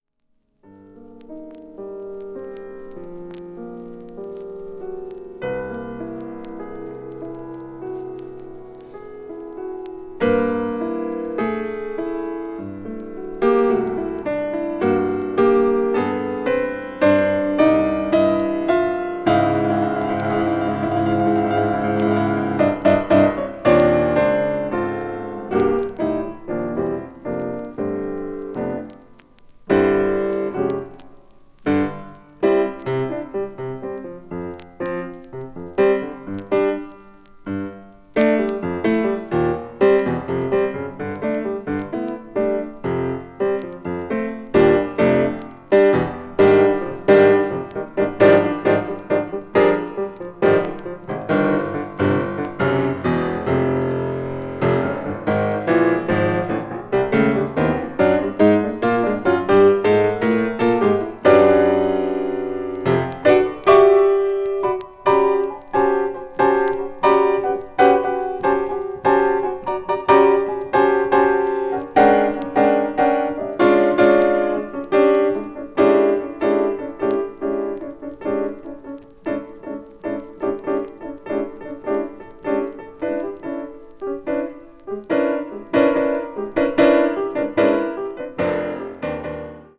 media : VG+/VG+(わずかなチリノイズ/軽いチリノイズが入る箇所あり)
avant-jazz   free improvisation   free jazz   piano solo